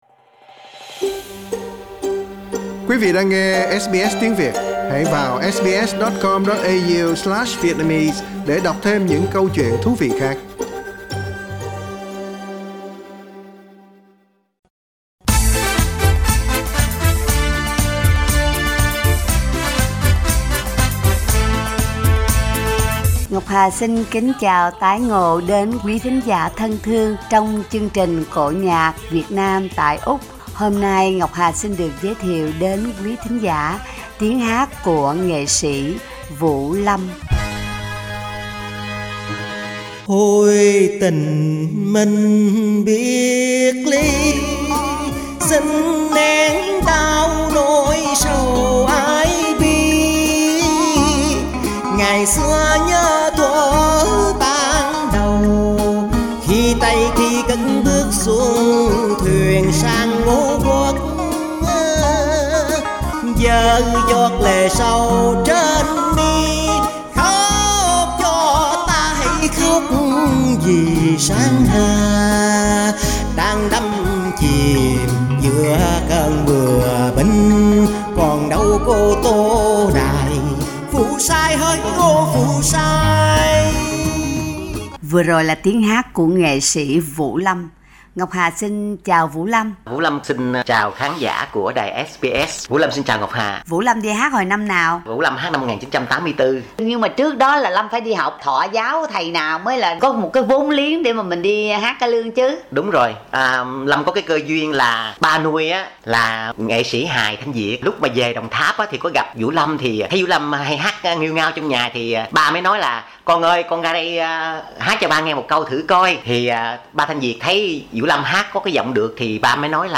Cổ nhạc Việt Nam tại Úc